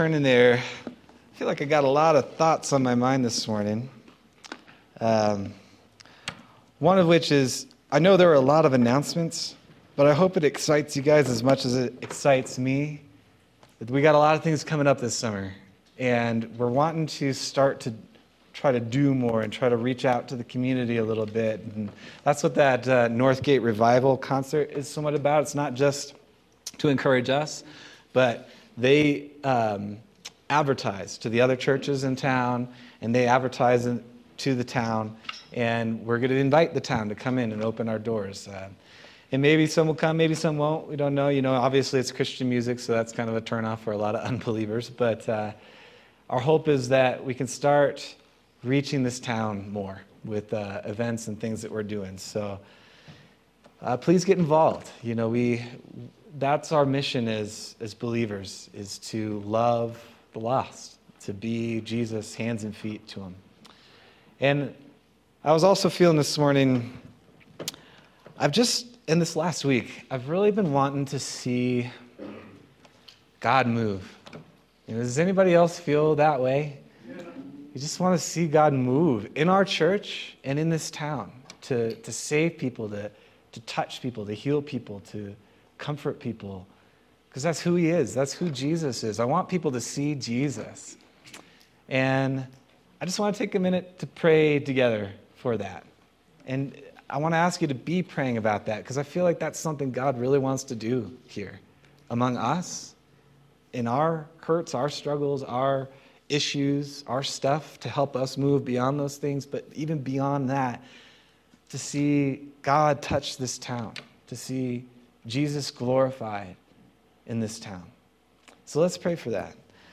May 4th, 2025 Sermon – Calvary Chapel Nederland